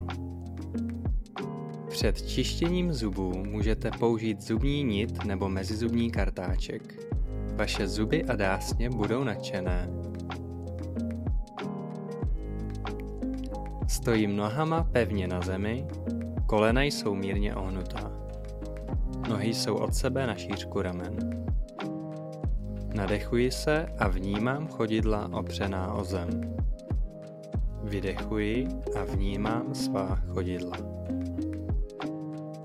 Svůj klidný hlas mohu nahrát na profesionální mikrofon a poslat Vám text, který si zvolíte, v audioformátu, který si vyberete.
Klidný mužský hlas